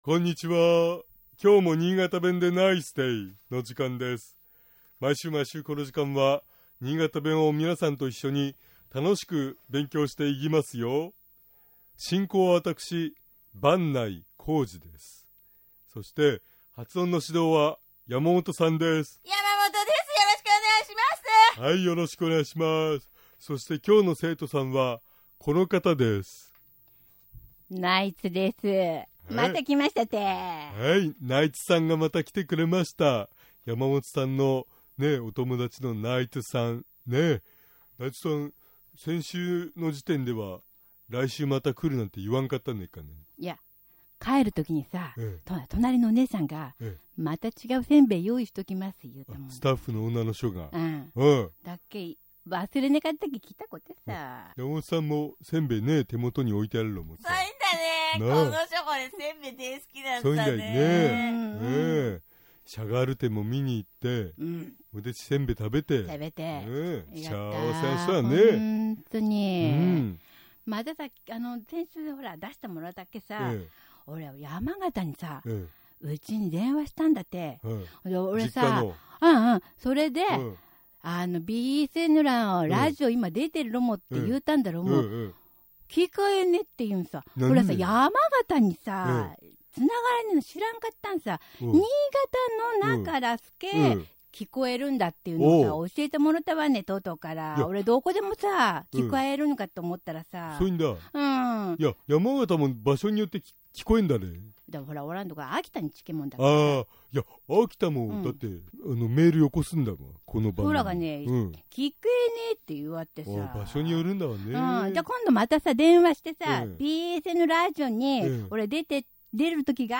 （スキット） （夫）おう、ちょうどいいとこにアイロンかけったな。